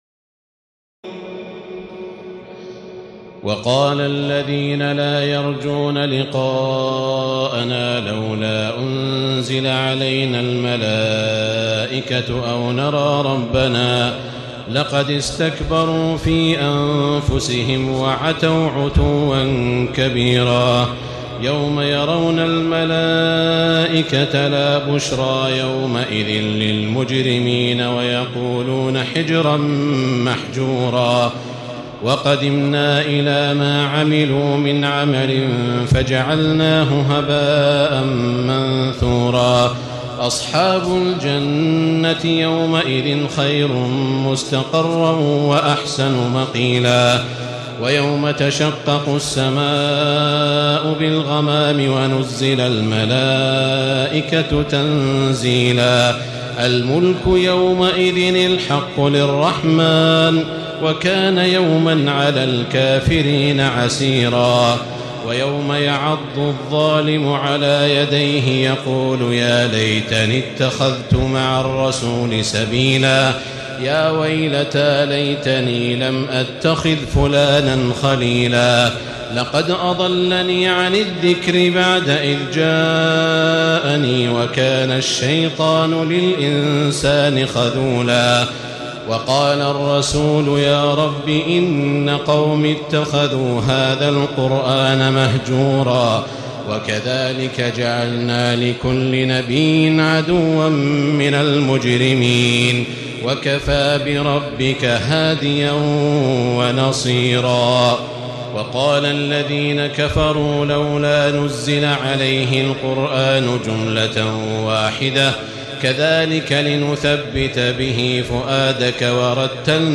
تراويح الليلة الثامنة عشر رمضان 1436هـ من سورتي الفرقان (21-77) و الشعراء (1-104) Taraweeh 18 st night Ramadan 1436H from Surah Al-Furqaan and Ash-Shu'araa > تراويح الحرم المكي عام 1436 🕋 > التراويح - تلاوات الحرمين